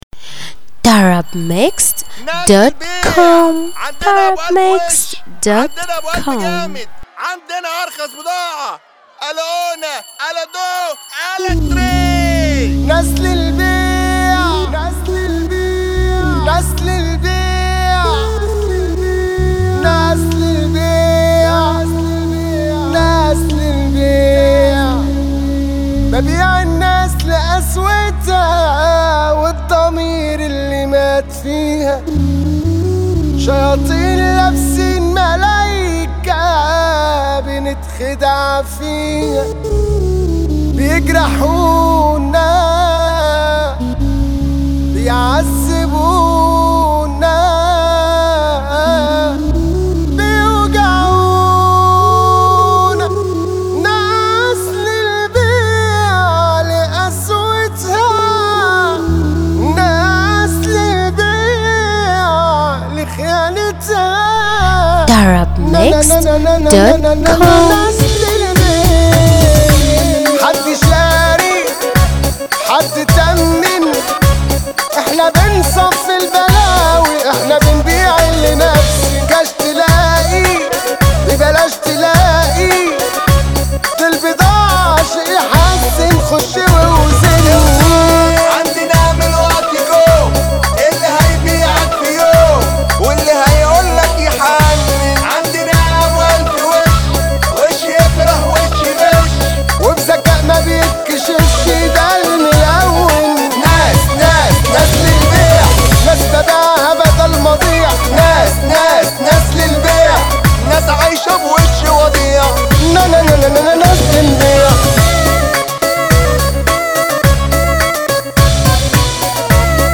النوع : shobeiat